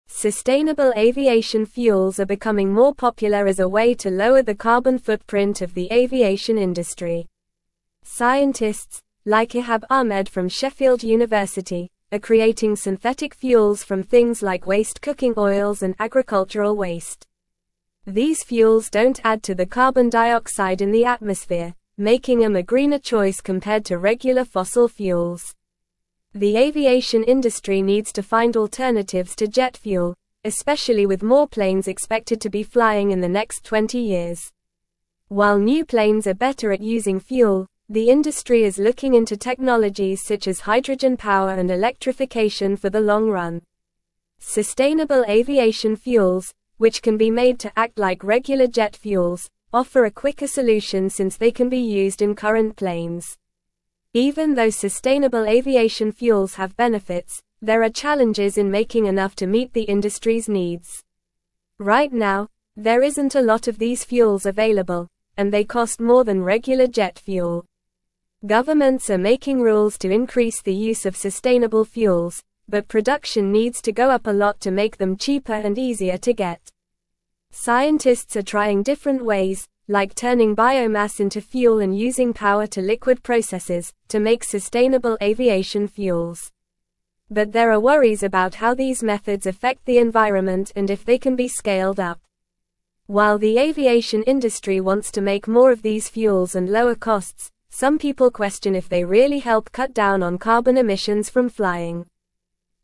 English-Newsroom-Upper-Intermediate-NORMAL-Reading-Advancements-in-Sustainable-Aviation-Fuels-for-Greener-Future.mp3